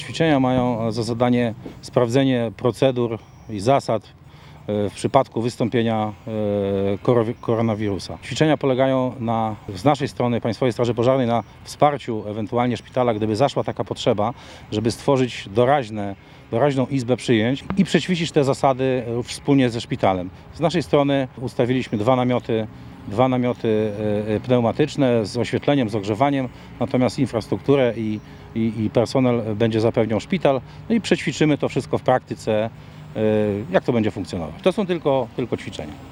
Ćwiczenia, których celem było sprawdzenie i doskonalenie działań podczas wystąpienia koronawirusa przeprowadzili w środę (04.03) przy suwalskim szpitalu miejscowi strażacy. Po południu mundurowi rozstawili przy szpitalu specjalne namioty przystosowane do opieki nad chorymi i prowadzili wspólne działania z personelem medycznym Szczegóły przedstawił Arkadiusz Buchowski, Komendant Miejski Państwowej Straży Pożarnej w Suwałkach.